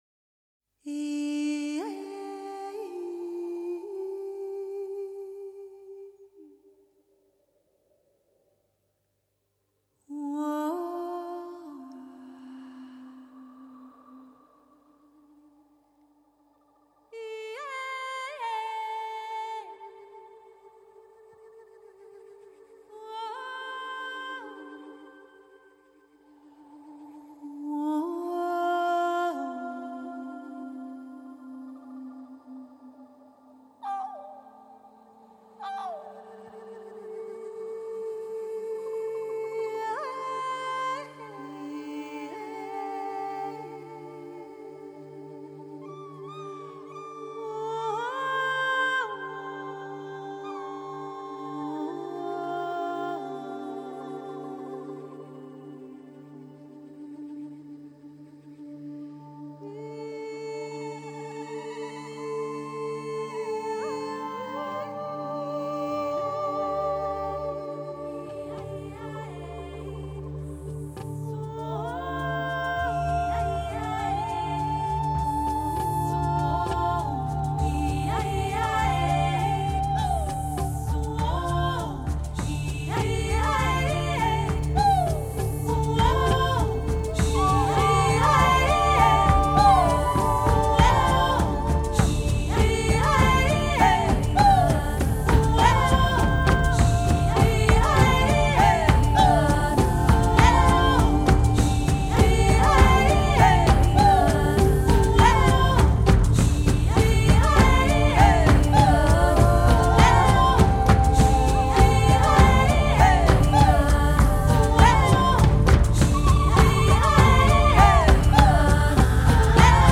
Theatre production
vocals, live electronics, soprano saxophone, piano
guitar, ukulele, bass